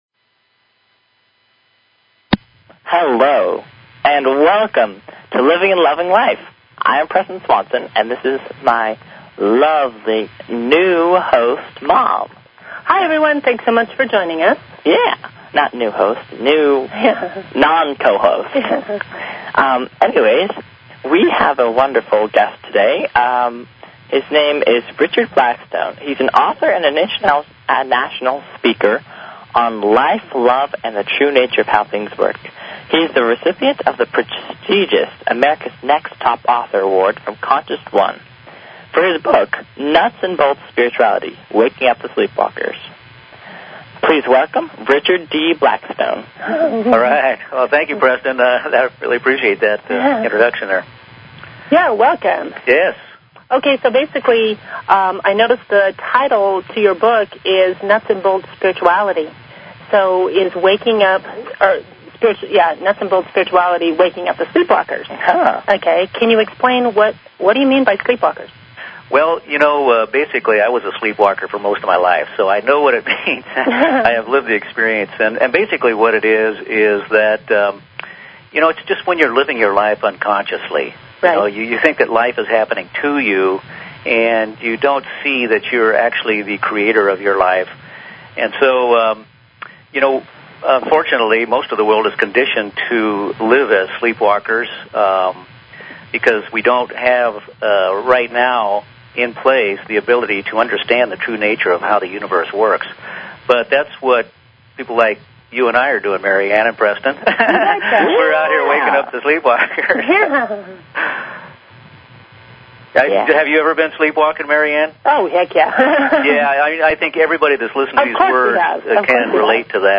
Talk Show Episode, Audio Podcast, Living_and_Loving_Life and Courtesy of BBS Radio on , show guests , about , categorized as